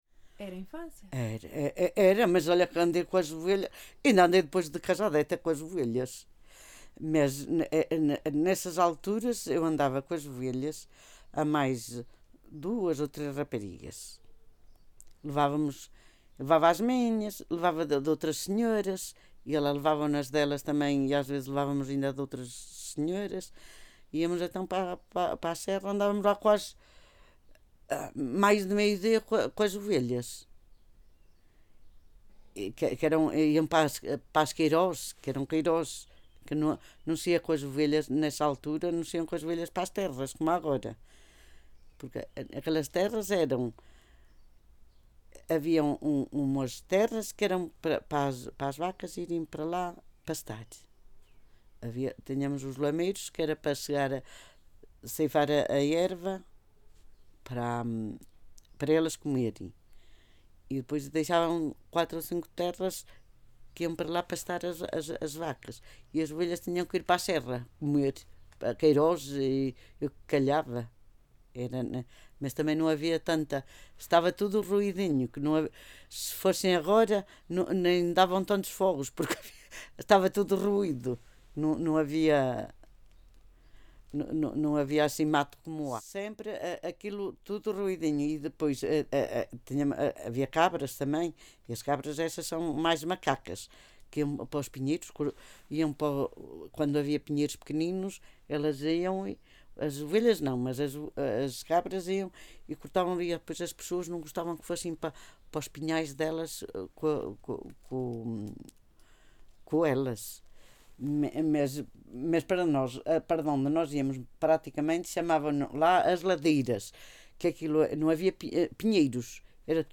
Várzea de Calde, primavera de 2019.
Tipo de Prática: Inquérito Oral